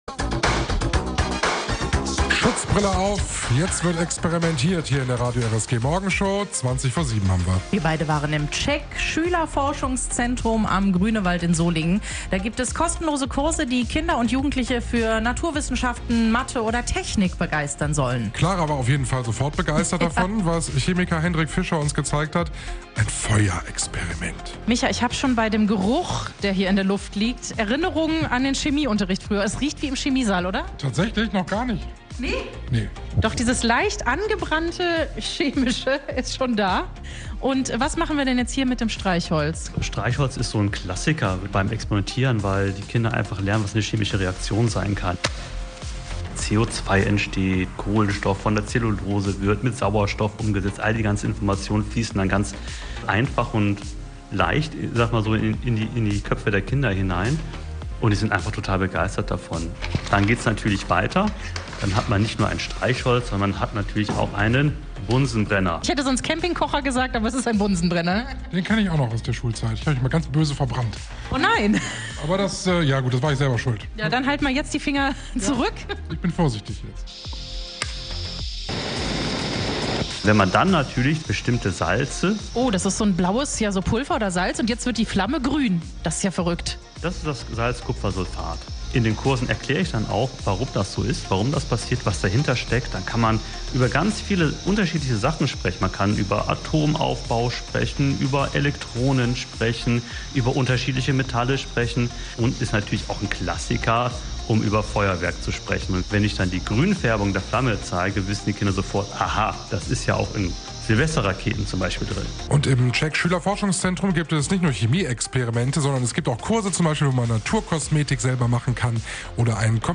Die beiden waren im Solinger Schülerforschungszentrum.